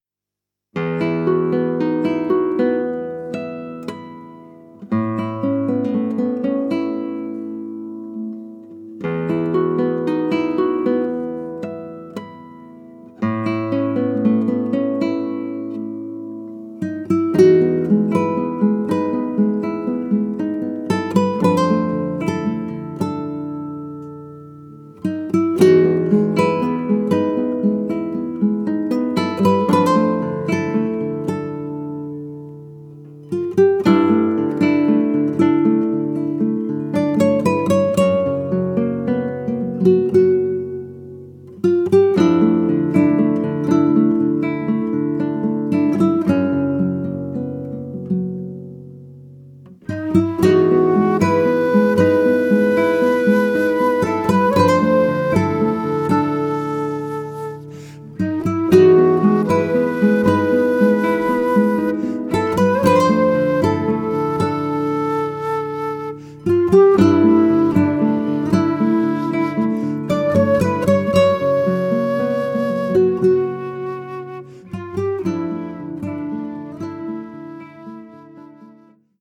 gitarre, bass, percussion
flöte
klarinette
cello